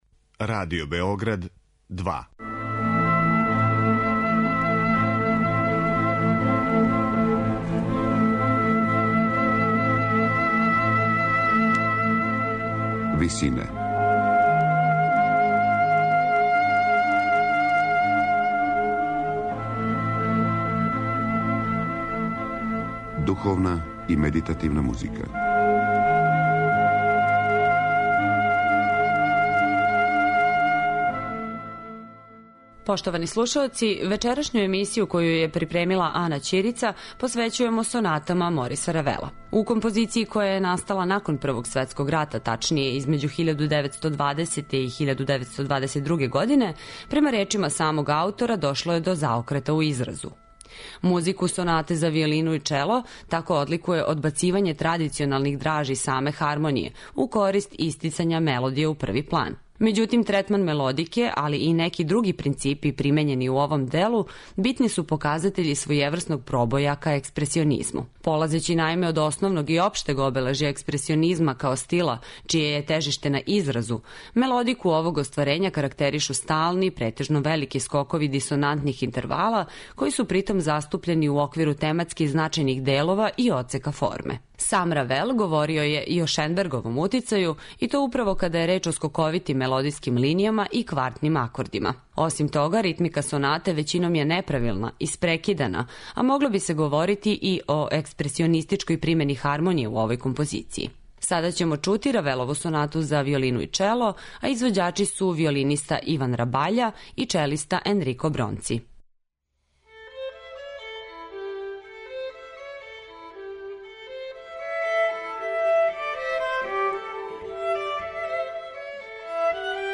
Две Равелове сонате
Сонату за виолину и виолончело